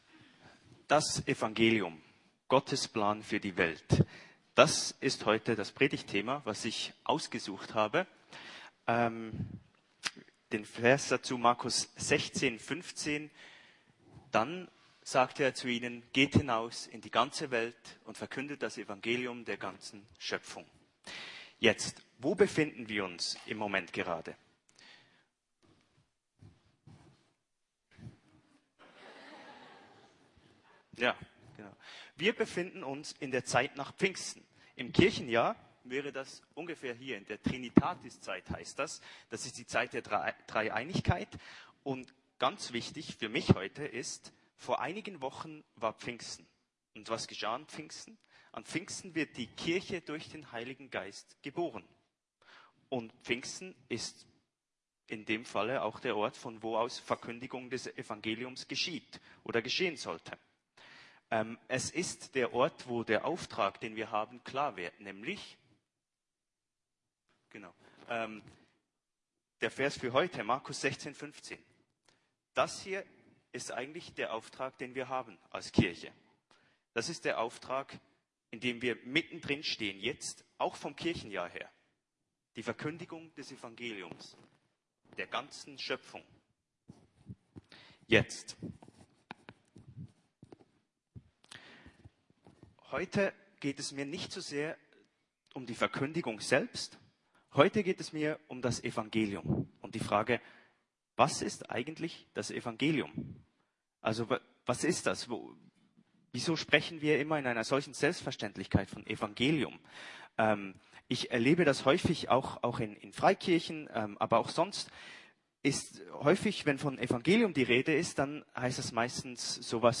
Das Evangelium - Gottes Plan für die Welt ~ Predigten der LUKAS GEMEINDE Podcast